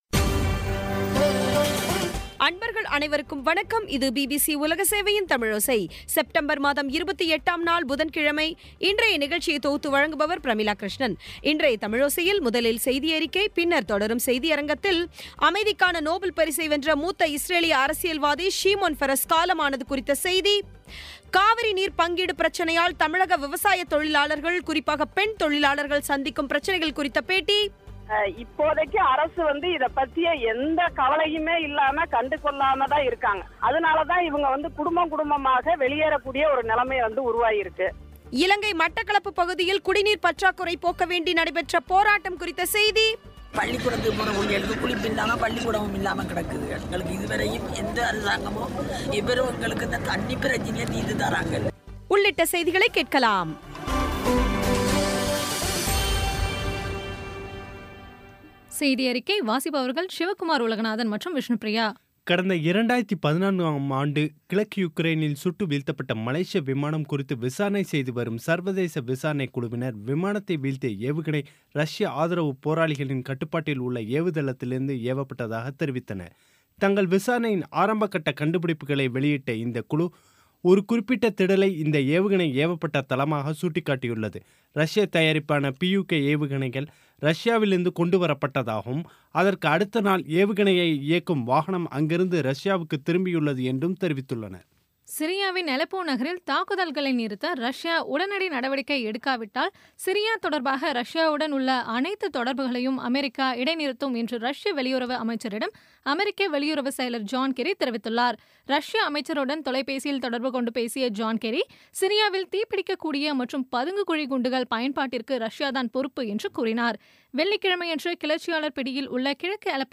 இன்றைய தமிழோசையில், முதலில் செய்தியறிக்கை, பின்னர் தொடரும் செய்தியரங்கத்தில் காவிரி நீர் பங்கீடு பிரச்சனையால், தமிழக விவசாயத் தொழிலாளர்கள் சந்திக்கும் பிரச்சனைகள் குறித்த பேட்டி இலங்கை மட்டக்களப்பு பகுதியில் குடிநீர் பற்றாக்குறையை போக்க வேண்டி நடைபெற்ற போராட்டம் குறித்த செய்தி ஆகியவை கேட்கலாம்